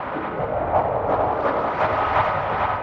Index of /musicradar/rhythmic-inspiration-samples/85bpm